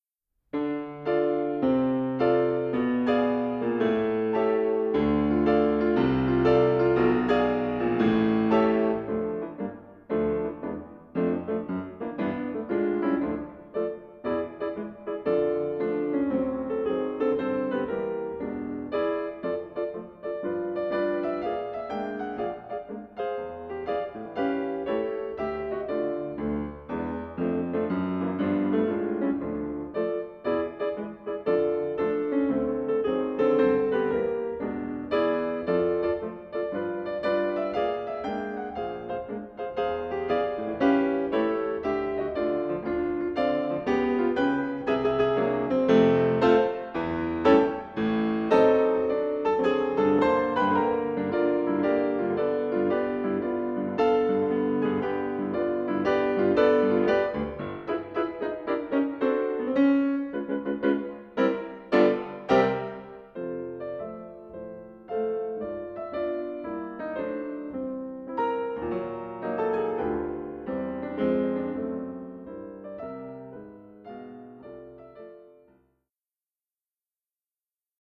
these solo piano works, both charming and imposing